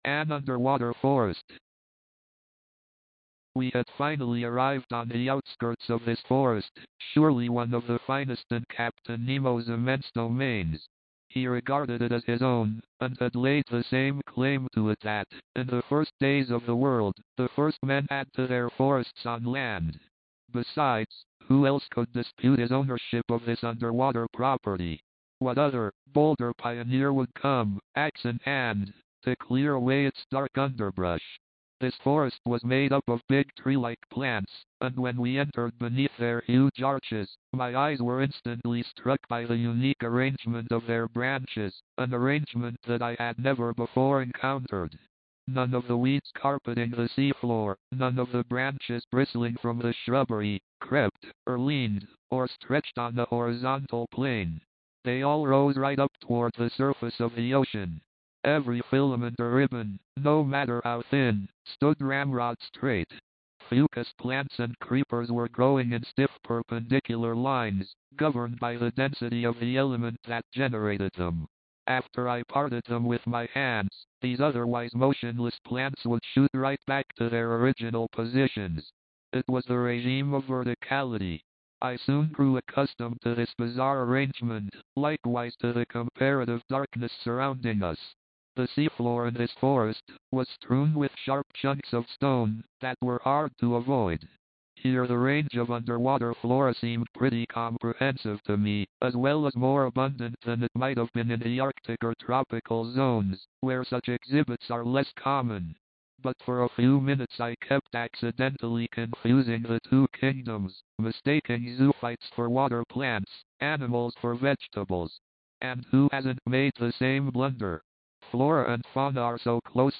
Verne's descriptions of the underwater world, with its exotic creatures and sunken ships, shine thanks to clear narration and evocative sound effects.
It picks up steam again with sequences involving a monstrous octopus and a storm. mp3, audiobook, audio, book Date Added: Dec/31/1969 Rating: Add your review